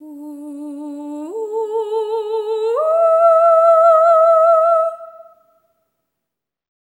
ETHEREAL08-L.wav